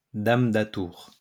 Audio pronunciation file from the Lingua Libre project.